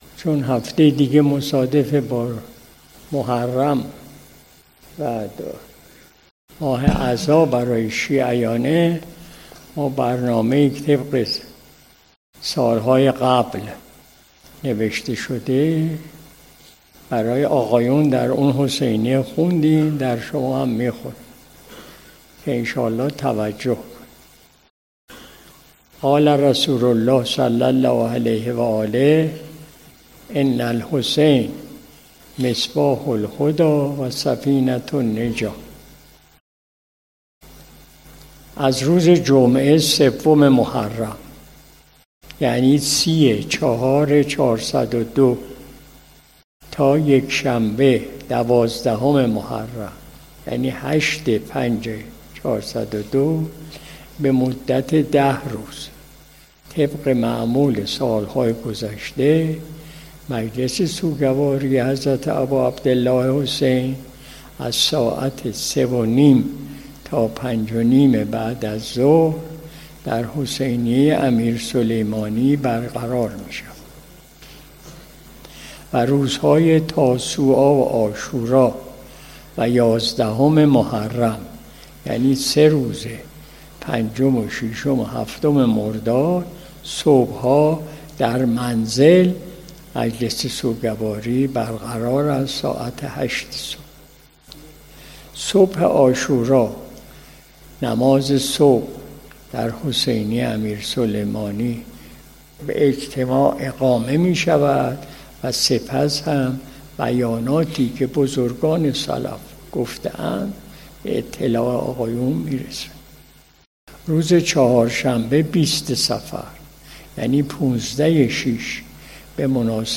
مجلس صبح جمعه ۲۳ تیر ماه ۱۴۰۲ شمسی